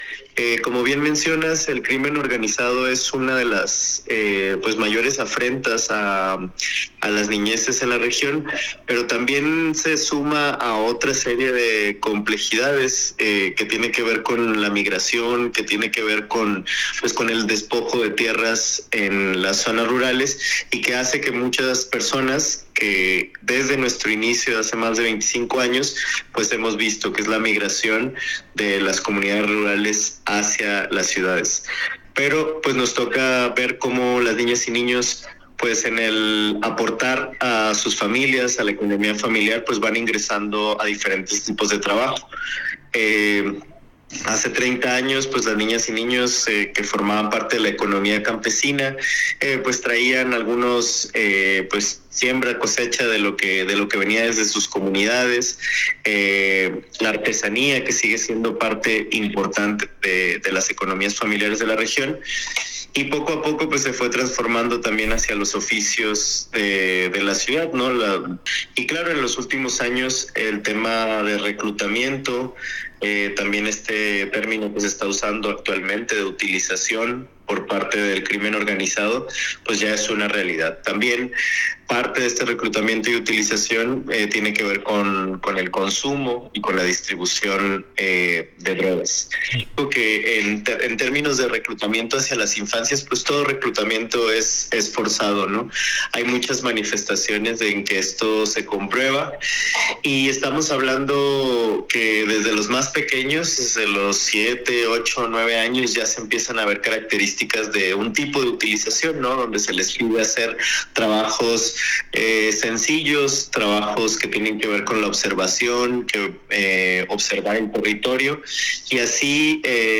La Voz Campesina - Radio Huaya